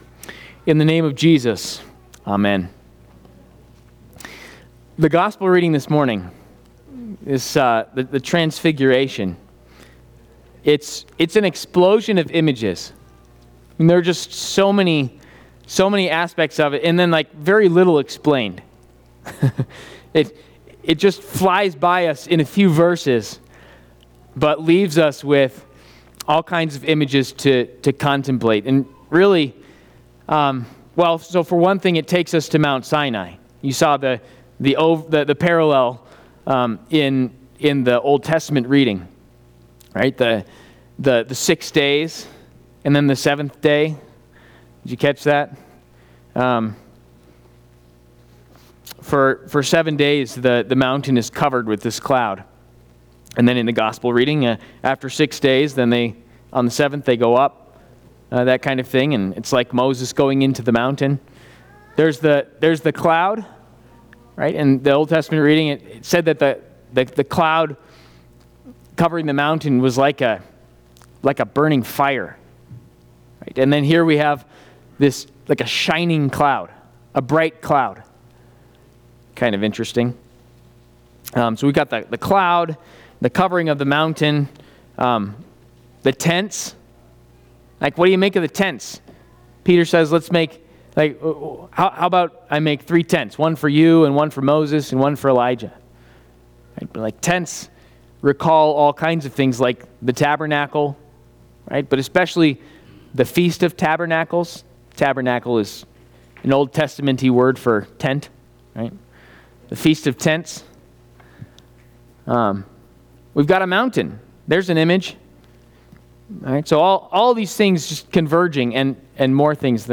Trinity Lutheran Church, Greeley, Colorado An Explosion of Images Feb 15 2026 | 00:18:01 1x 00:00 / 00:18:01 Subscribe Share RSS Feed Share Link Embed